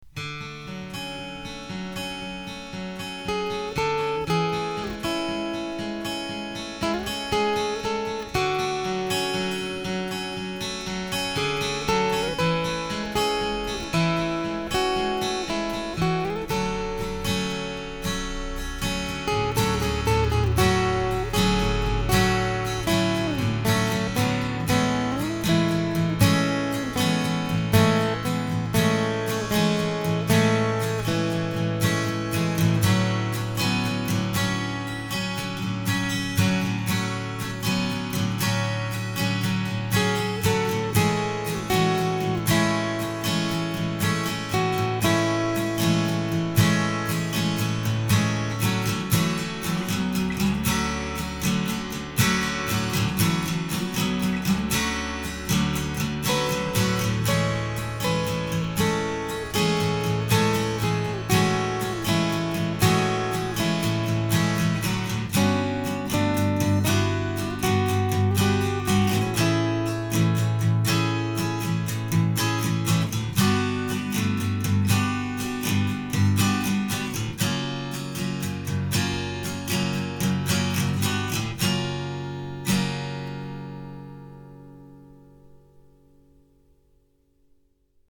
Aucun souffle ,vraiment incroyable, et un rendu de toute beauté.
Ma folk donc ici :